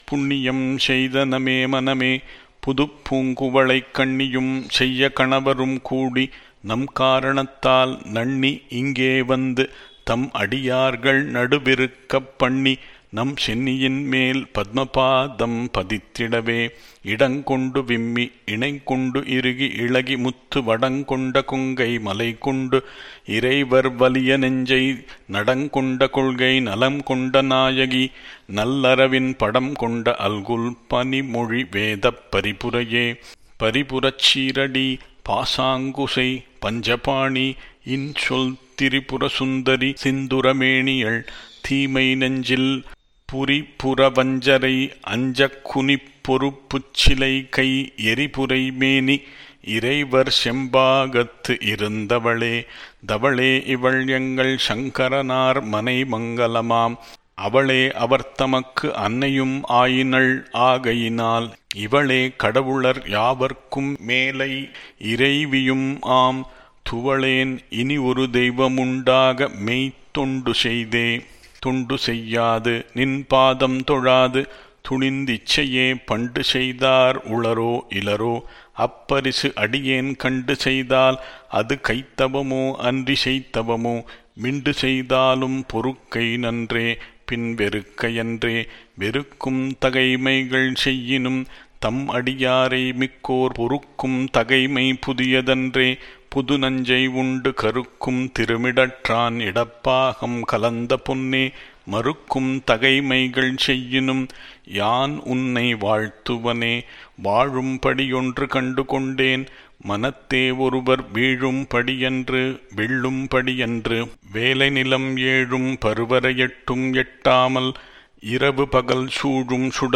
Stothra Parayanam Audio